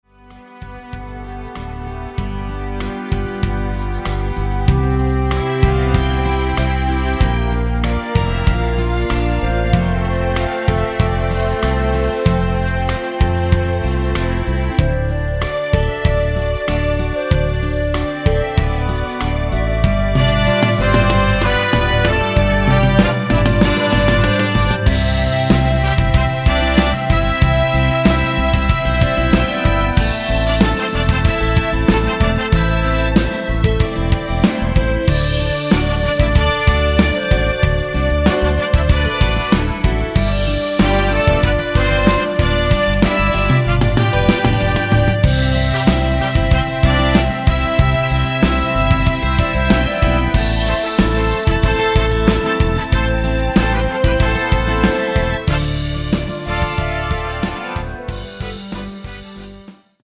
원키(-7) 내린 남자키 멜로디 포함된 MR 입니다.(미리듣기 참조)
멜로디 MR이라고 합니다.
앞부분30초, 뒷부분30초씩 편집해서 올려 드리고 있습니다.
중간에 음이 끈어지고 다시 나오는 이유는